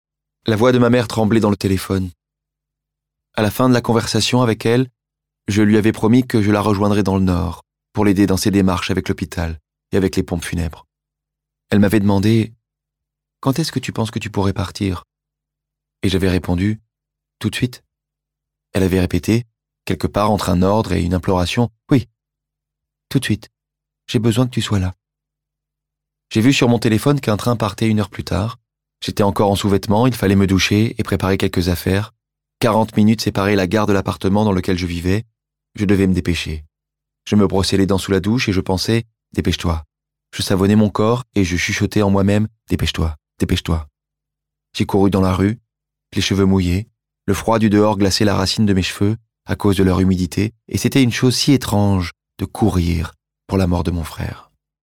« L’effondrement » d’Edouard Louis, lu par Loïc Corbery de la Comédie-Française